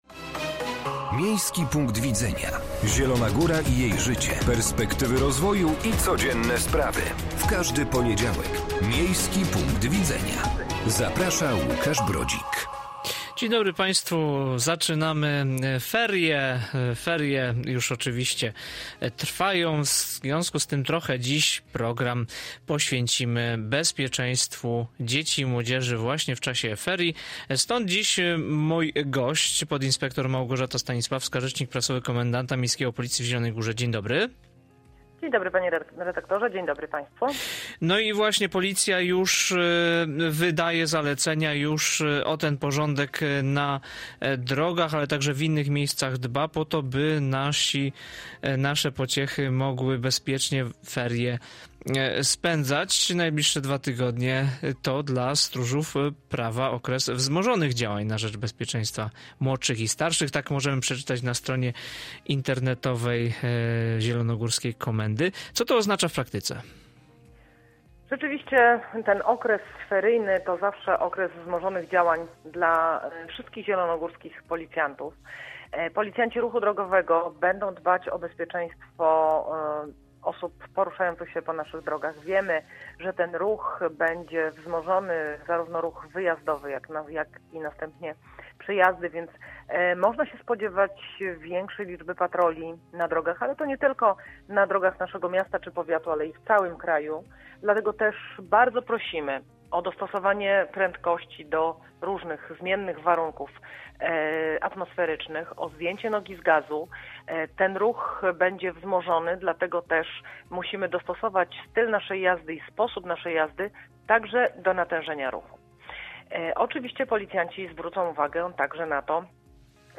Gośćmi audycji były: - podinsp.